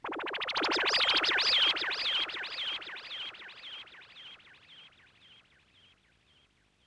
Microwave 3.wav